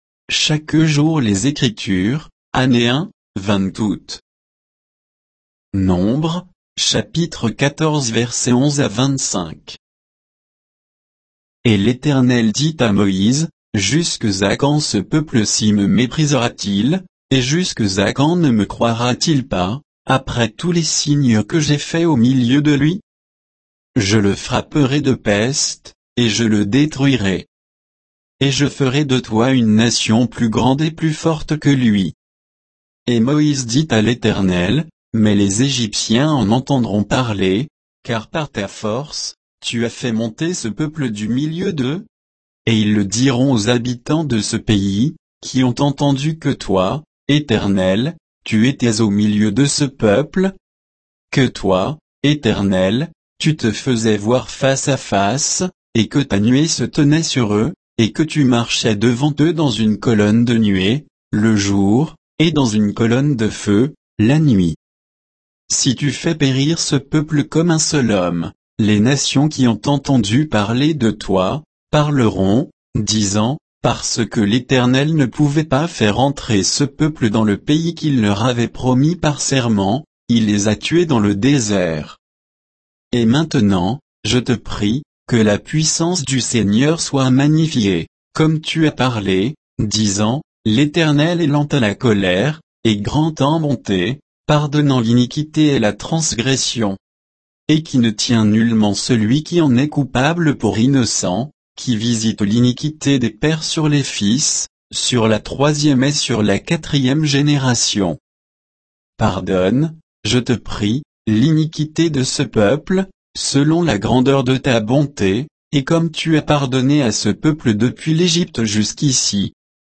Méditation quoditienne de Chaque jour les Écritures sur Nombres 14